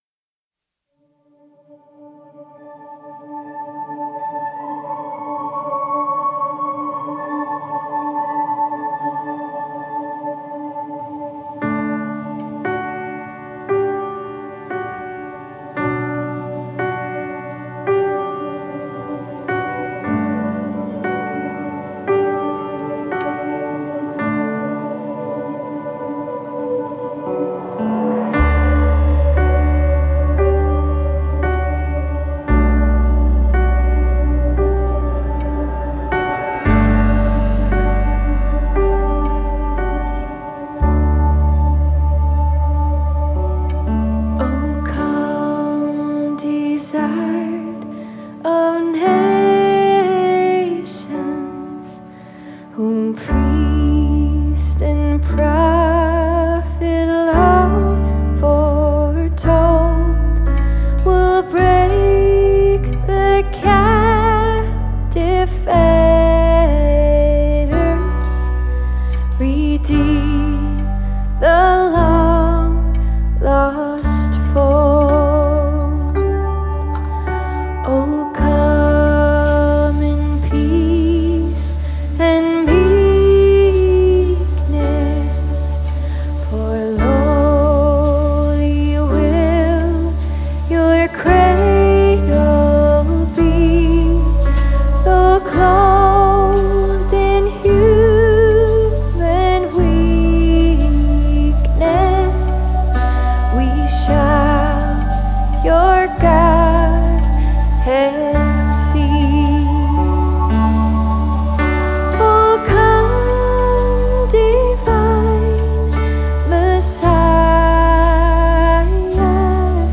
Advent hymn